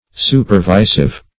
Supervisive \Su`per*vi"sive\, a.